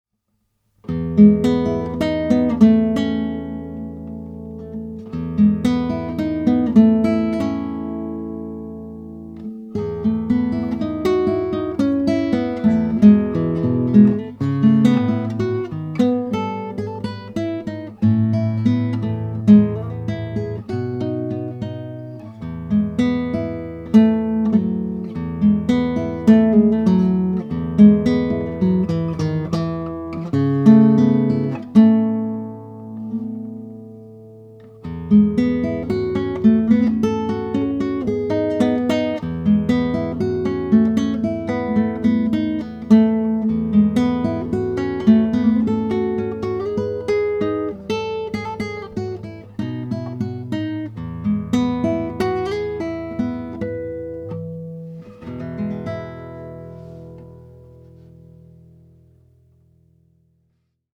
Here we have a small ribbon microphone from Aiwa in nice working condition.
And nylon string guitar (also me)
Aiwa_VM13_Guitar.mp3